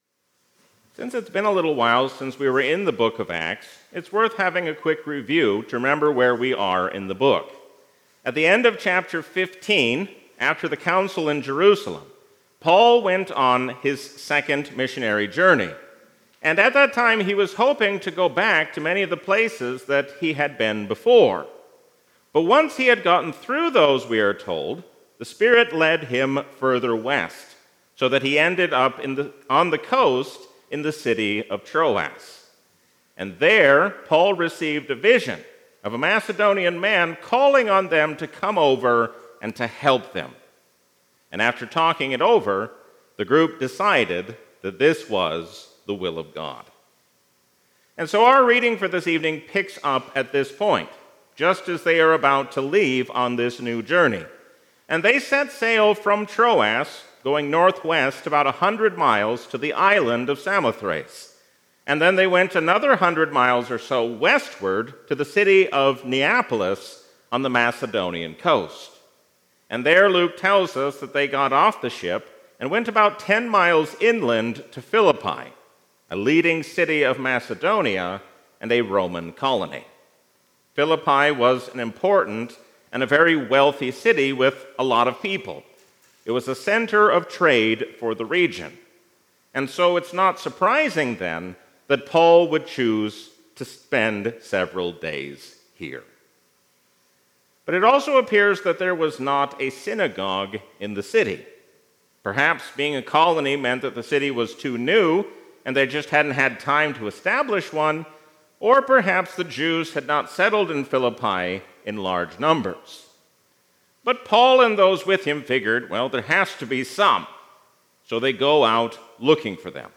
A sermon from the season "Epiphany 2026." Always be on guard against error hiding itself behind the truth.